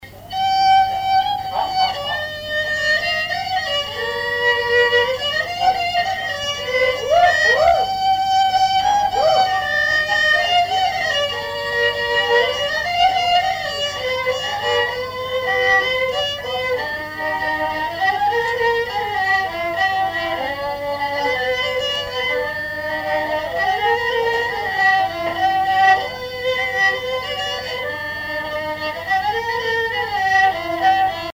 Mémoires et Patrimoines vivants - RaddO est une base de données d'archives iconographiques et sonores.
danse : valse lente
Répertoire d'un bal folk par de jeunes musiciens locaux
Pièce musicale inédite